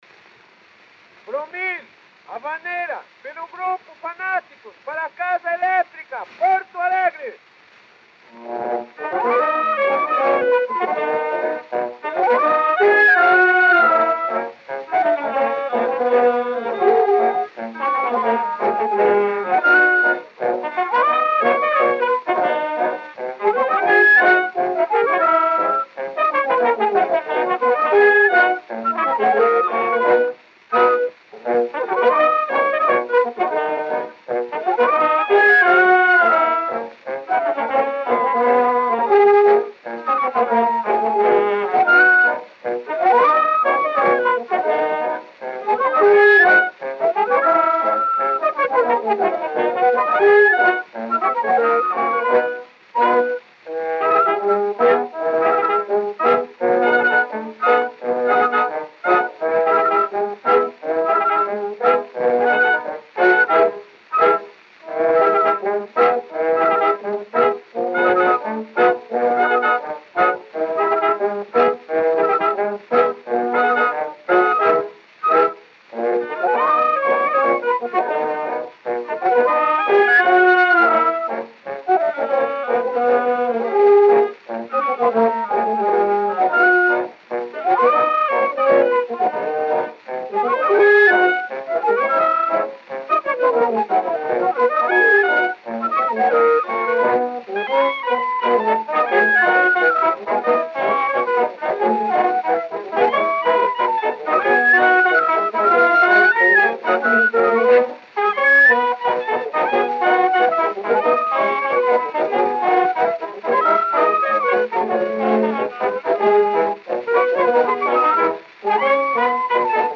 O gênero musical foi descrito como "Havaneira".